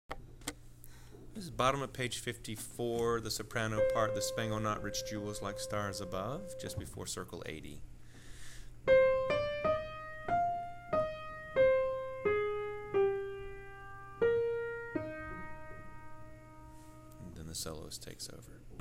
07 80 Sop part
07-80-Sop-part.wma